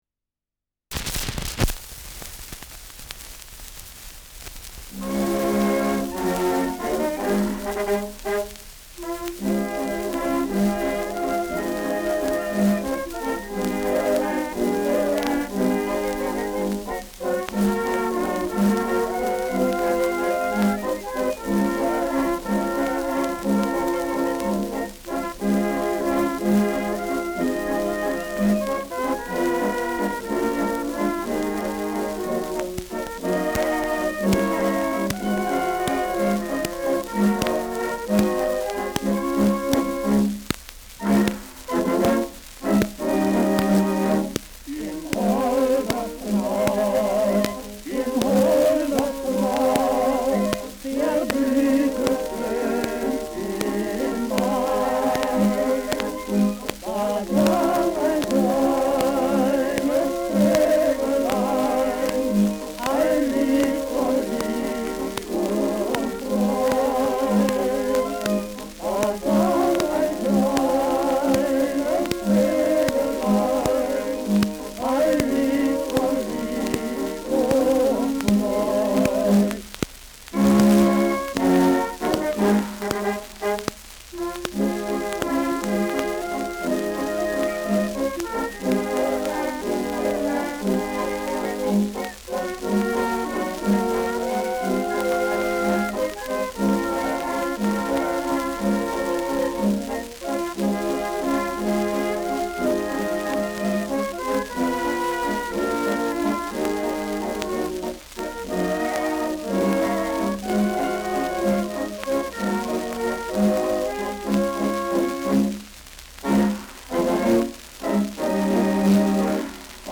Walzerlied
Schellackplatte
präsentes Rauschen : Knacken
mit Refraingesang